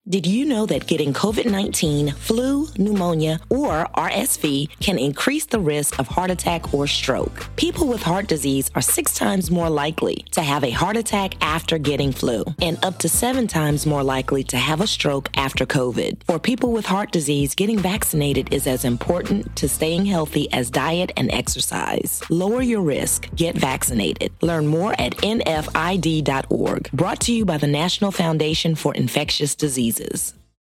Throughout February, NFID will observe American Heart Month by reminding iHeartRadio listeners that getting vaccinated is good for your heart:
NFID-Heart-Disease-Month-Audio-PSA-Female.mp3